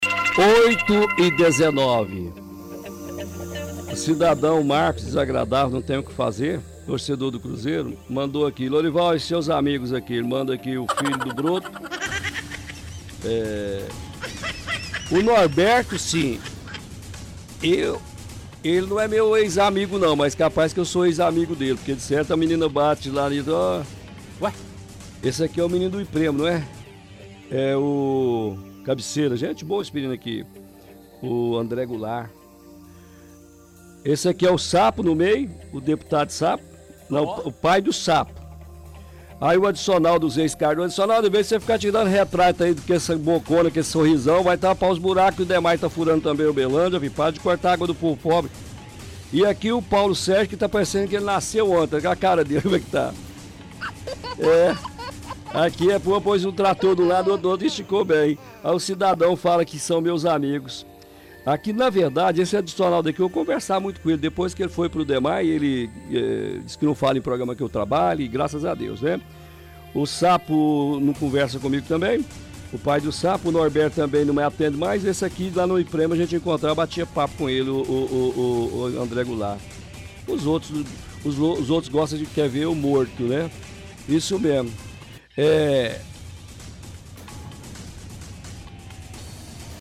Home / Rádio / Abertura